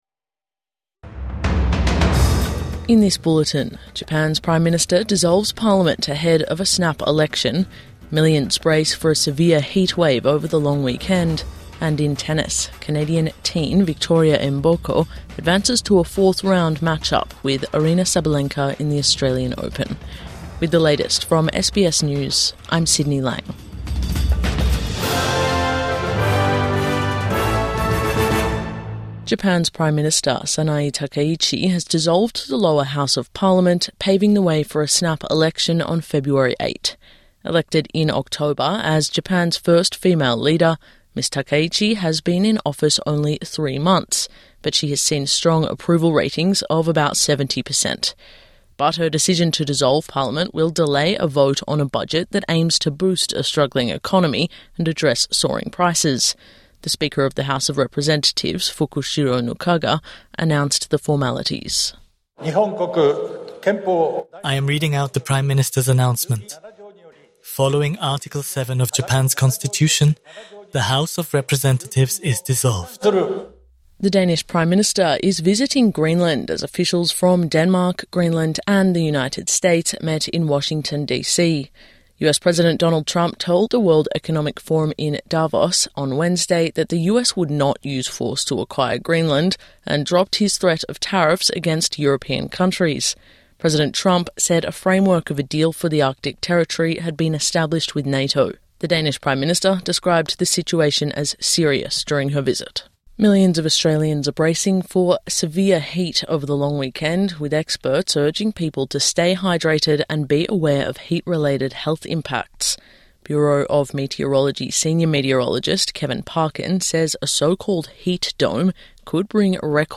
Japan's Prime Minister dissolves parliament ahead of snap election | Morning News Bulletin 24 January 2026